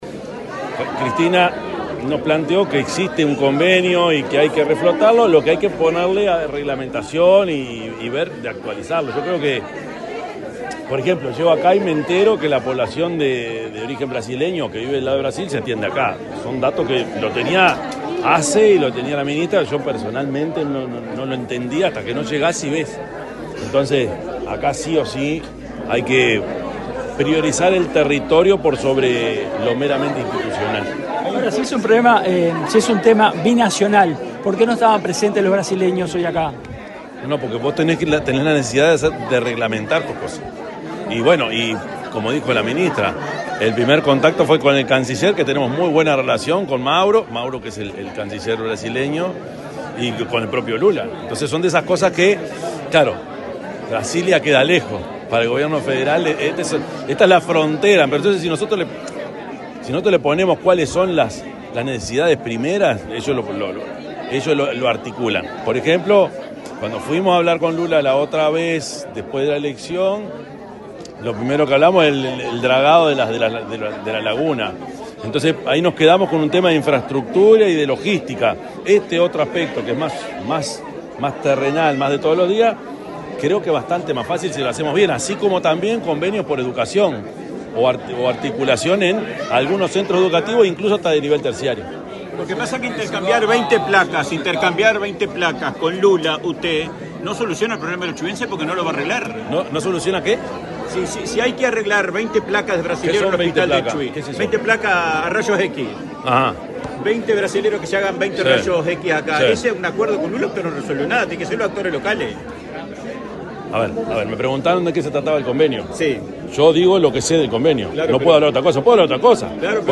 Declaraciones del presidente de la República, Yamandú Orsi 02/05/2025 Compartir Facebook X Copiar enlace WhatsApp LinkedIn El presidente de la República, Yamandú Orsi, dialogó con la prensa en el centro auxiliar del Chuy, departamento de Rocha, donde encabezó el lanzamiento de la Comisión Binacional Asesora de Frontera.